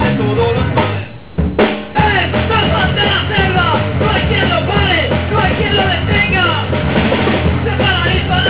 El grup està format per bateria, baix, guitarra i veu.